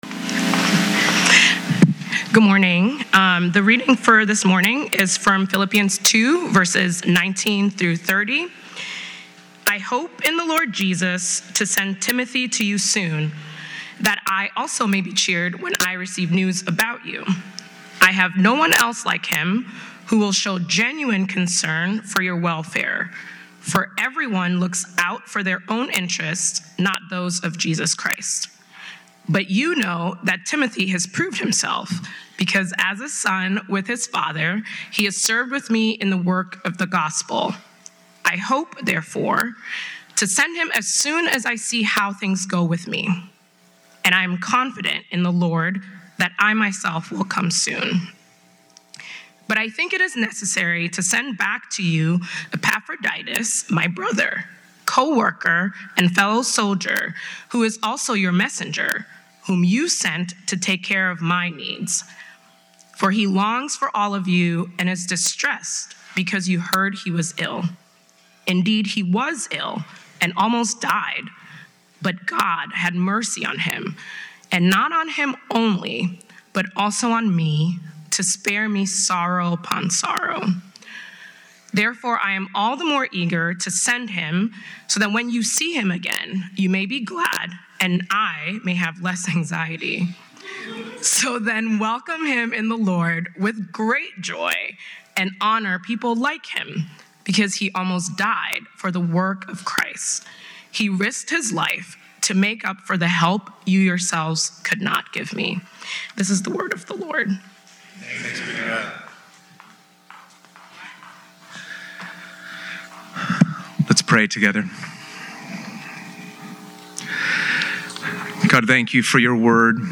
The fifth sermon in a series on Philippians where we learn about the sacrifical, serving nature of leadership.